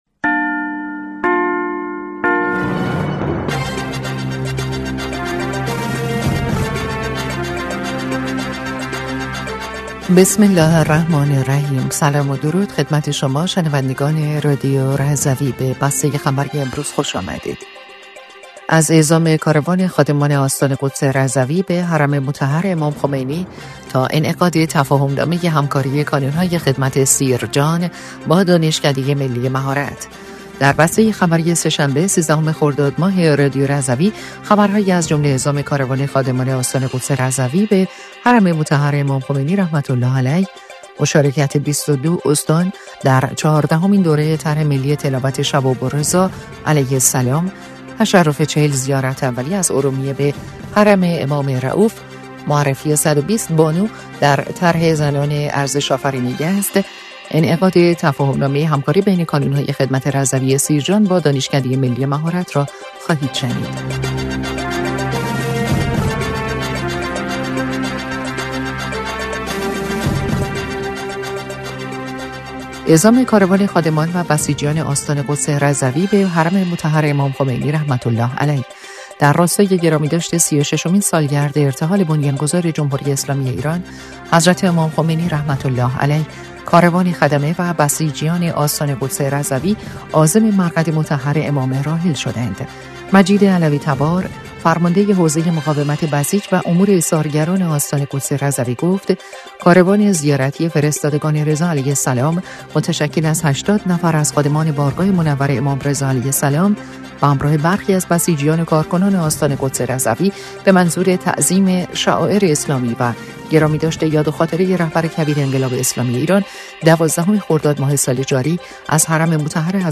در بسته خبری سه‌شنبه ۱۳ خرداد رادیو رضوی خبرهایی از جمله اعزام کاروان خادمان آستان قدس رضوی به حرم مطهر امام خمینی، مشارکت ۲۲ استان در چهاردهمین دوره طرح ملی تلاوت شباب‌الرضا (ع)، تشرف ۴۰ زیارت اولی از ارومیه به حرم امام رئوف، معرفی ۱۲۰ بانو در طرح زنان ارزش‌آفرین یزد و انعقاد تفاهم‌نامه همکاری بین کانون‌های خدمت رضوی سیرجان با دانشکده ملی مهارت را خواهید شنید.